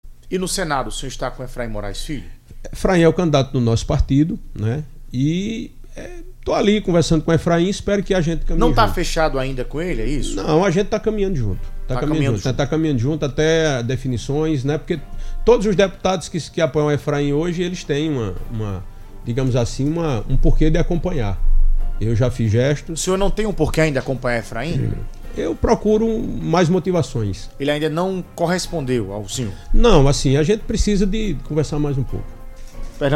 O deputado Federal Julian Lemos (União Brasil) em entrevista na noite dessa quinta-feira (28), disse que ainda não está concretizado o apoio ao pré-candidato ao Senado Federal e presidente da legenda na Paraíba, deputado Efraim Filho.